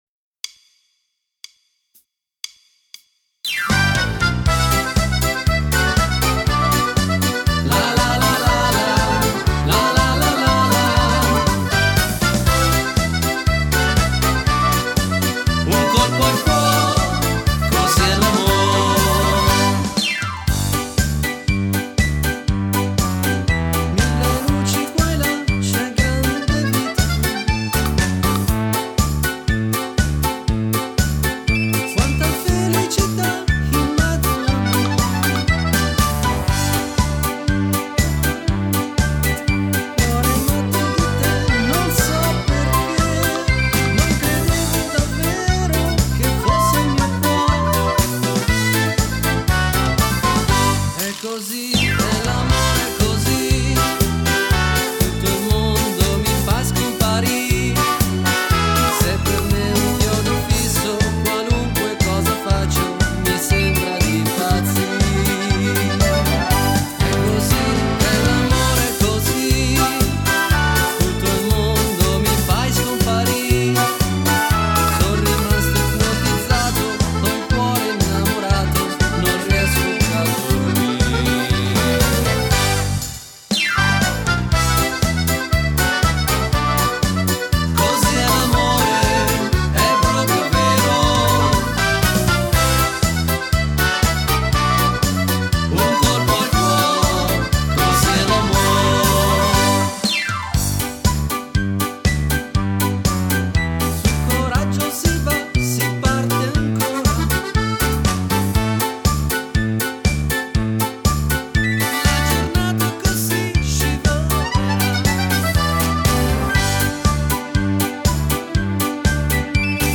Valzer
Uomo